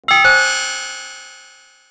War Chime.wav